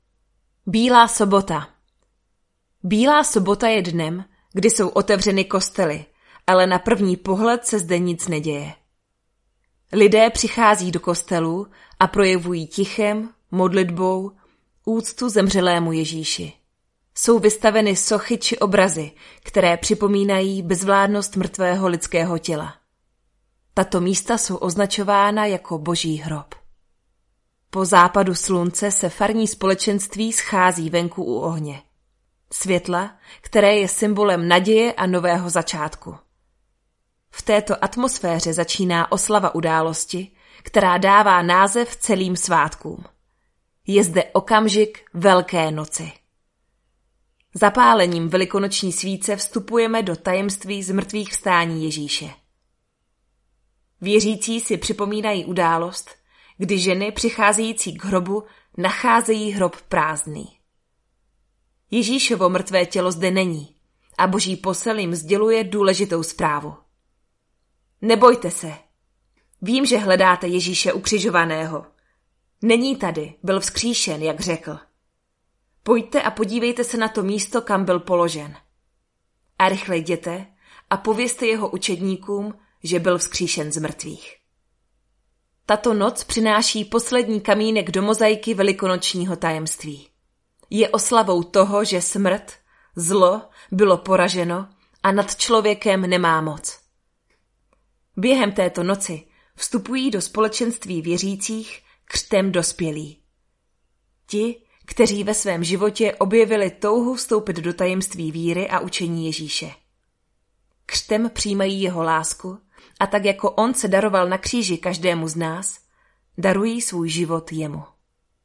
Lektorské úvody k triduu